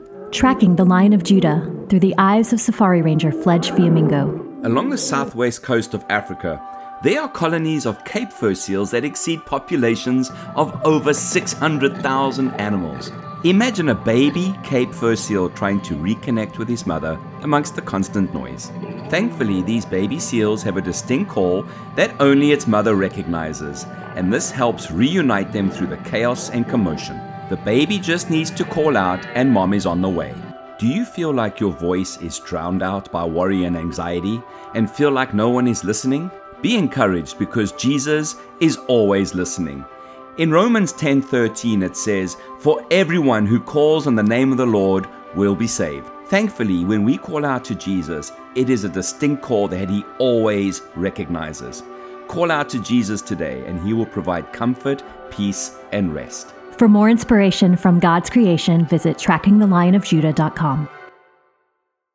TTLOJ-Cape-Fur-Seal-Calls-amongst-the-chaos-.m4a